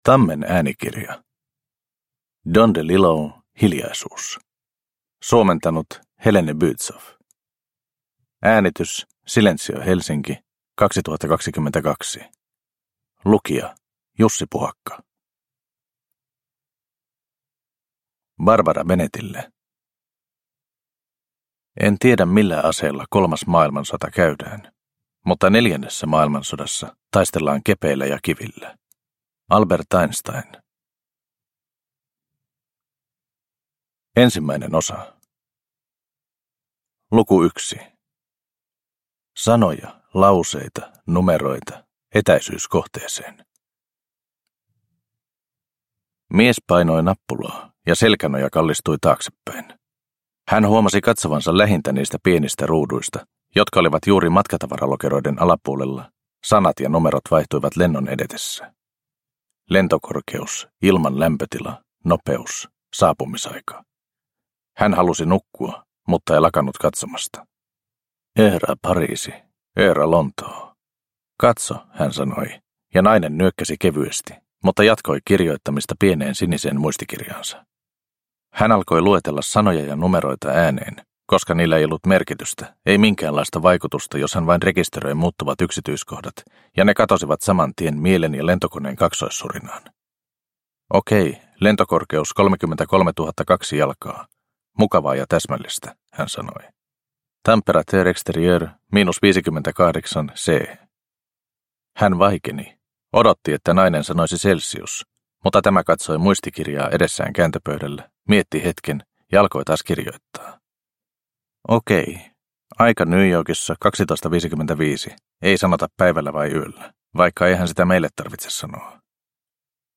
Hiljaisuus – Ljudbok – Laddas ner